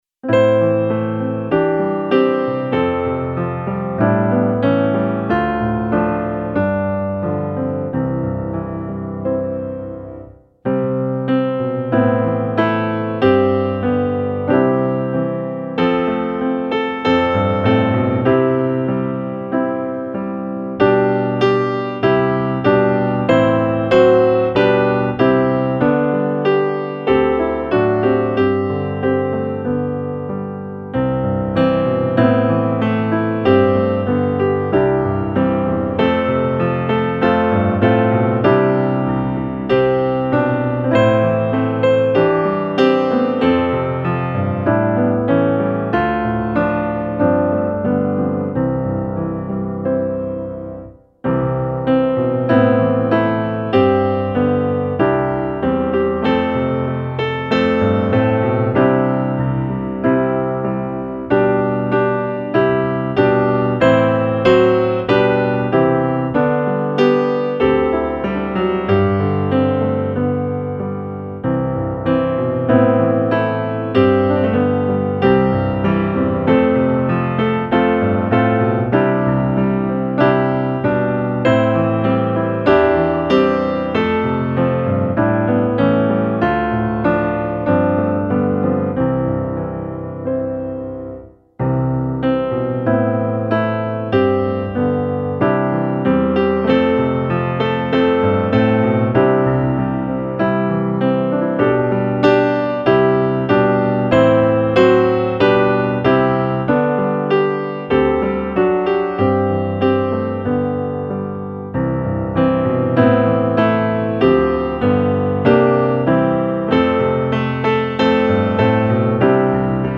Gemensam sång
Musikbakgrund Psalm